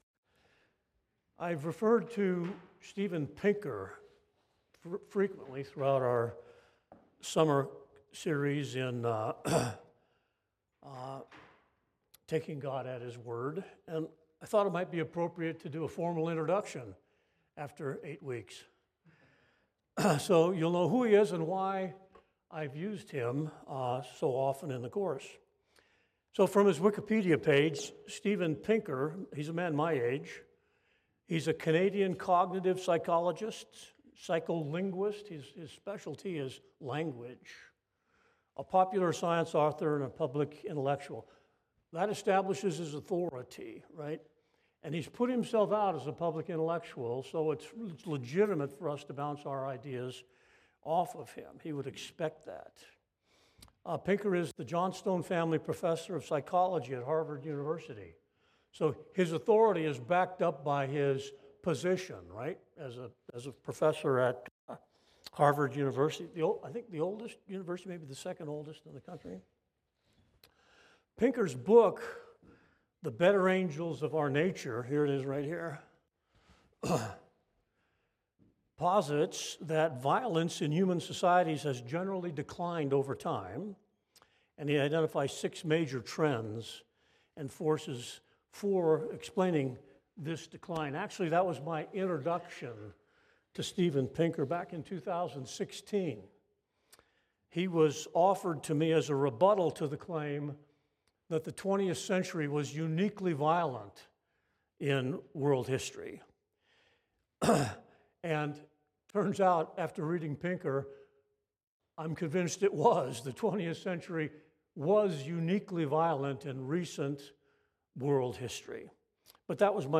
Lesson 9 (Sunday School)